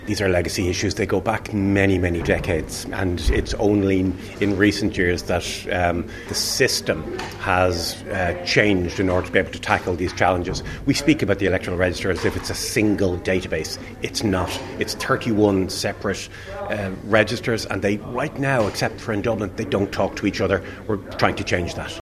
The Chief Executive of the Commission is Art O’Leary…………..